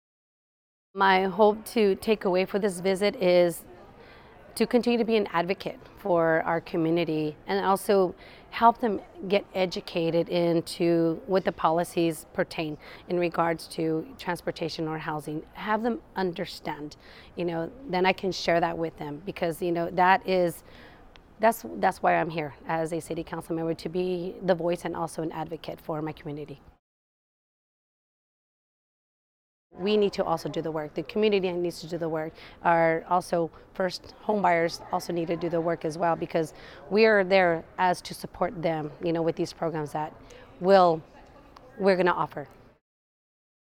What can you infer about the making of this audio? For radio news outlets who would like to air this story, the following links are soundbites of the State Legislative Issues Day in English and Spanish